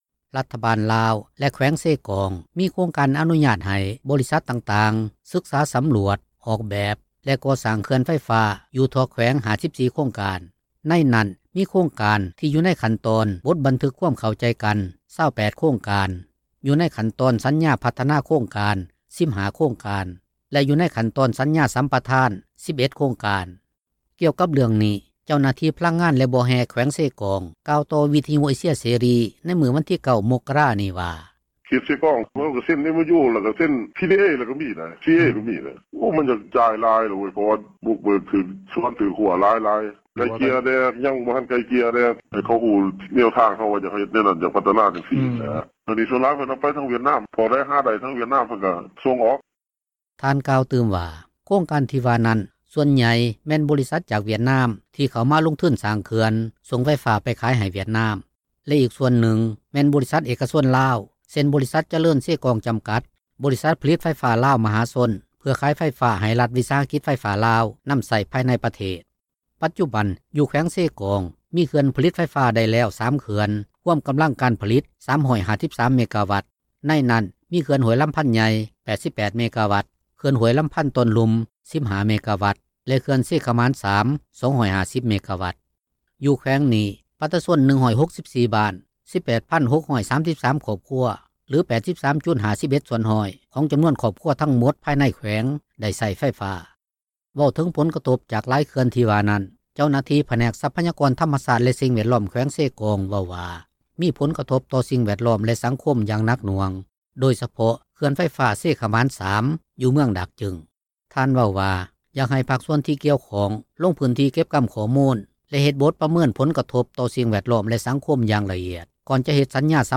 ກ່ຽວກັບເຣື່ອງນີ້ ເຈົ້າໜ້າທີ່ພະລັງງານ ແລະບໍ່ແຮ່ແຂວງເຊກອງ ກ່າວຕໍ່ວິທຍຸເອເຊັຽເສຣີ ໃນມື້ວັນທີ 9 ມົກກະຣານີ້ວ່າ: